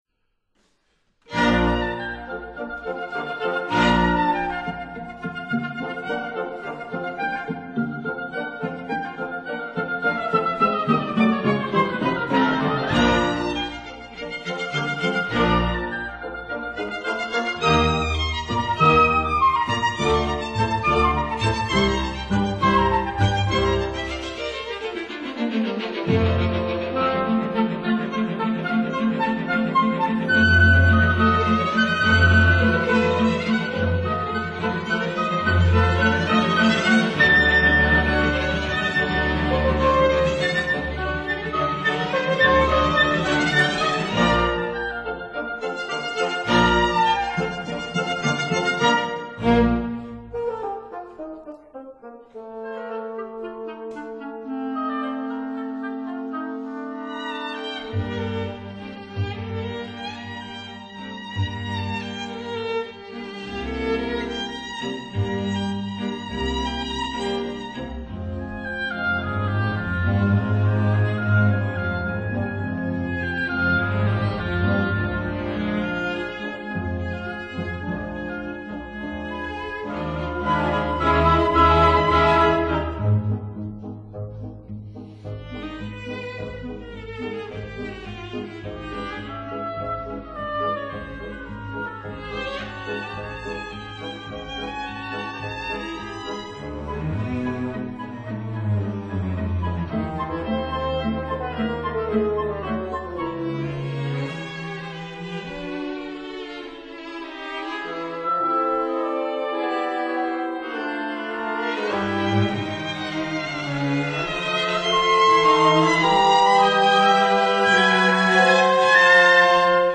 violin
viola
cello
flute
clarinet
oboe
bassoon
horn
double bass